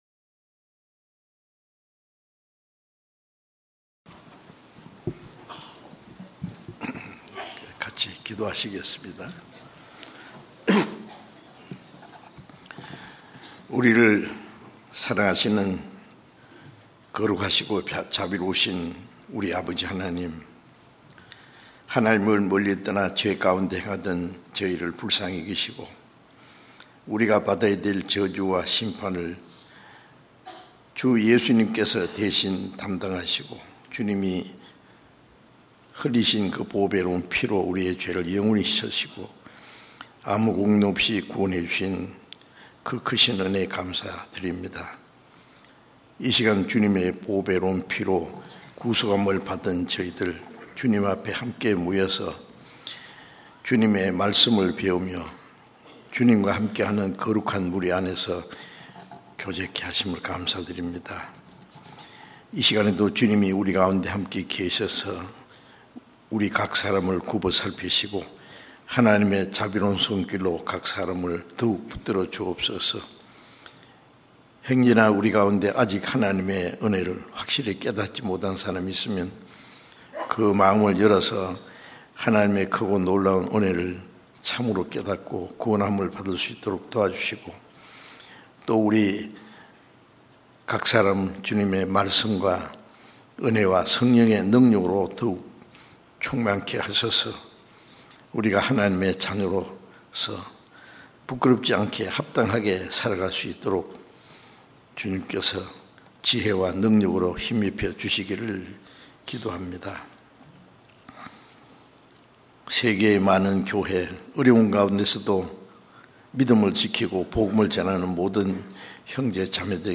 주일설교수요설교 (Audio)